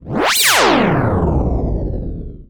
SCIFI_Sweep_04_mono.wav